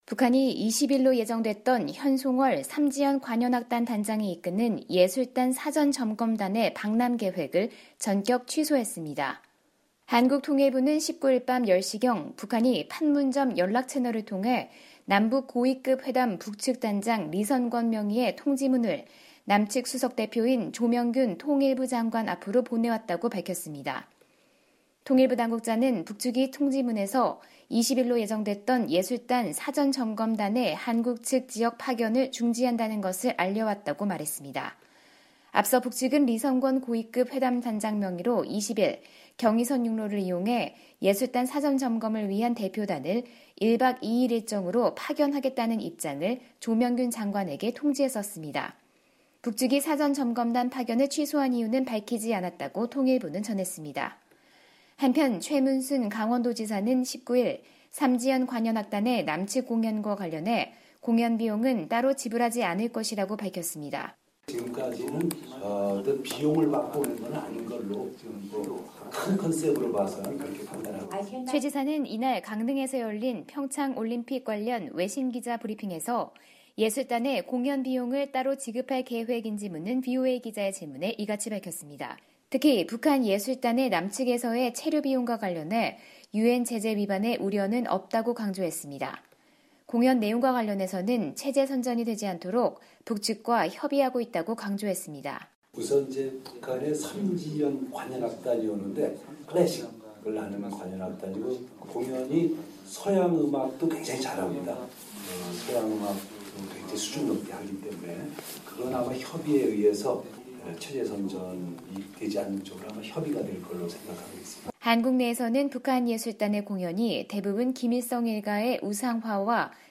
[특파원 리포트] 북한, 현송월 등 예술단 사전점검단 한국 방문 전격 취소